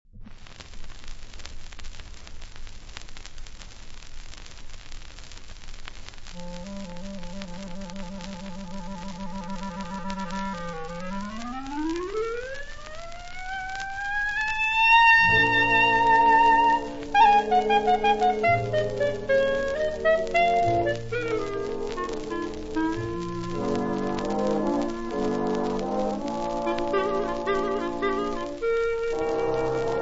• rapsodie
• Rhapsody
• registrazione sonora di musica